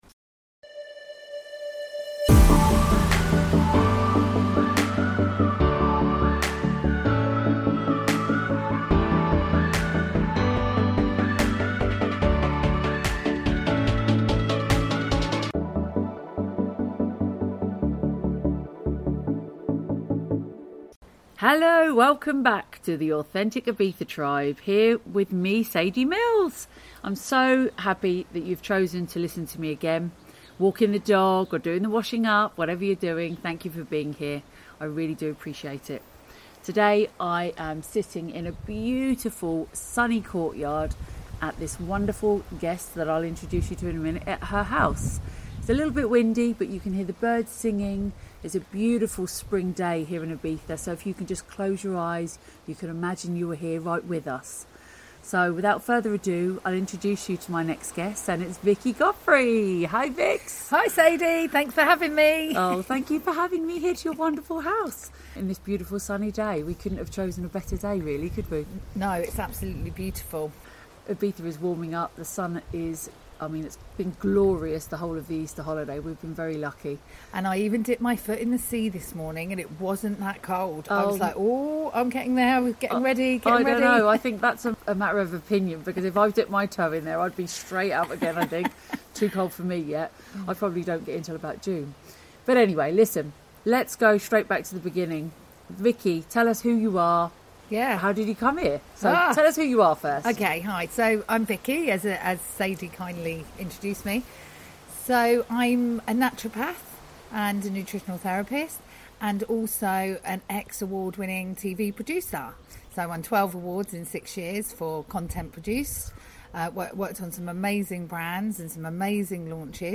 Intro and warm welcome from the terrace in the sunshine